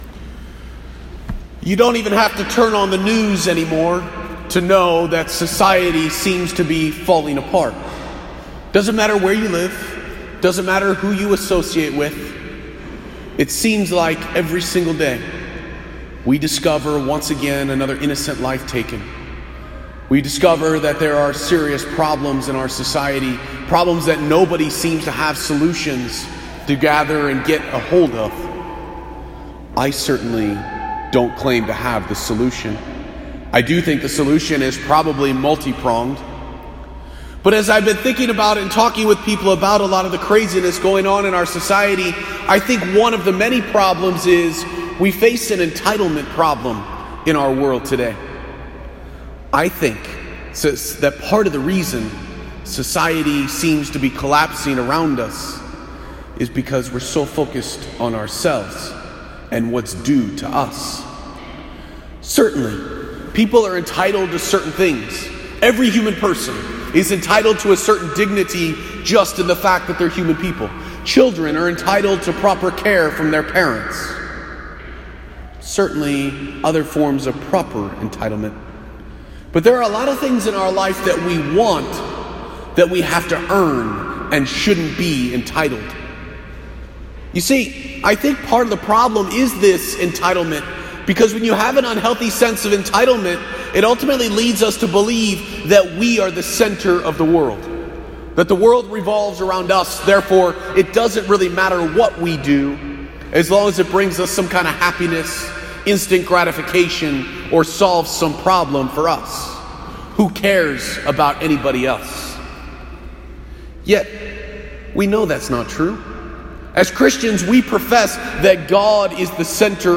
Posted in Homily